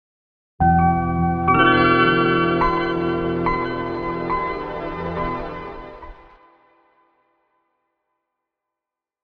Microsoft Windows 95 Startup Sound.ogg